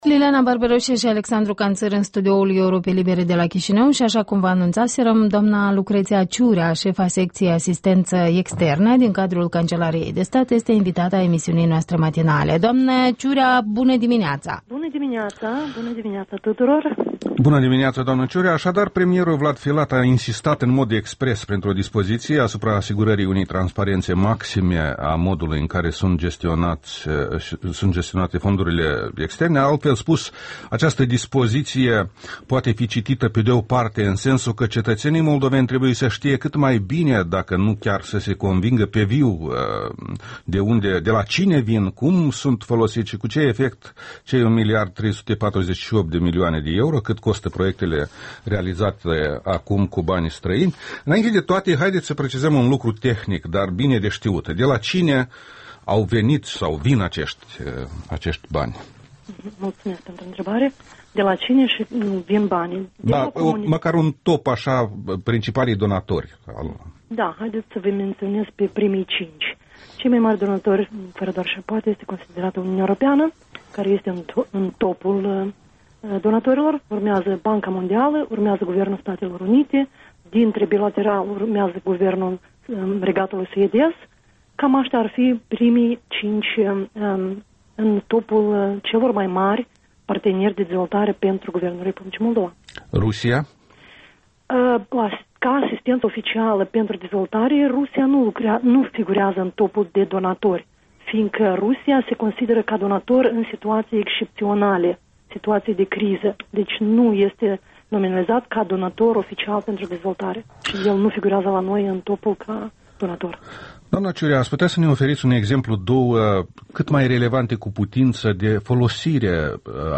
Interviul matinal